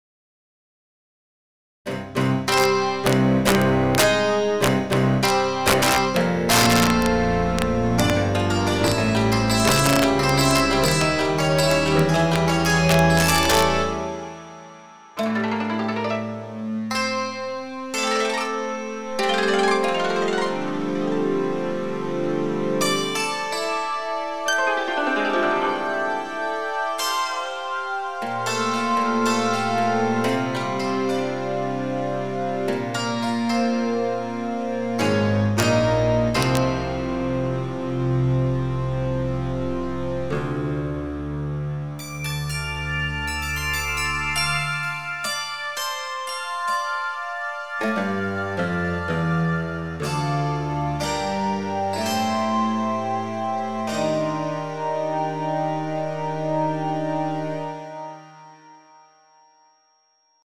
(about a minute, 256kbps mp3, stereo, 48k.)
This example takes an impulse revern of a space (concert hall) and one of a cabinat (if I remember correctly) adds that effect to two simulataneous instrument sample sets in LinuxSampler (Gigasampler) and a synthesier simulation, with the special part added that a mastering effect s used to like a PA system feed back the signal from the reverberation to the point of a little less than feedback (like micrphones), so that a "live" amplification feel comes in the signal.
Pretty heavy effect, and musically usable.